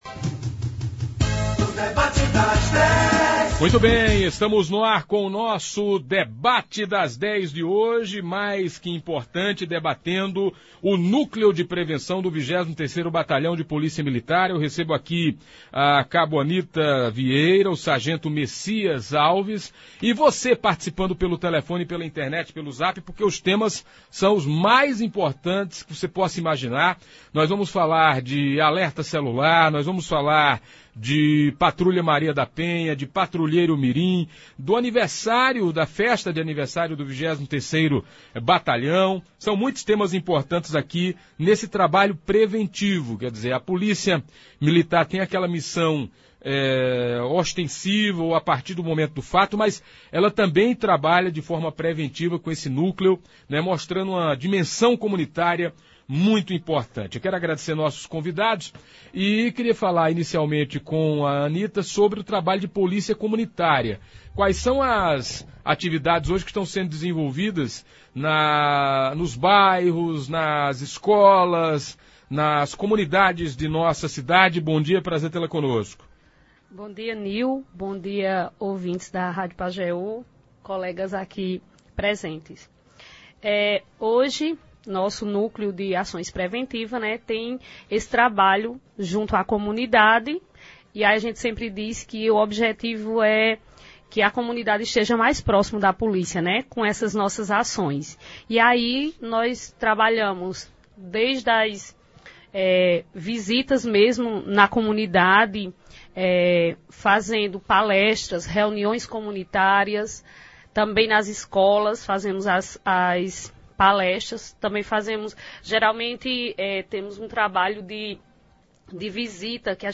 No bojo foi falado sobre o funcionamento do Alerta Celular, das ações da Patrulha Maria da Penha, sobre o programa Patrulheiro Mirim, do funcionamento da Polícia Comunitária e das festividades em comemoração do aniversário do 23 BPM. Ouça abaixo a íntegra do debate de hoje: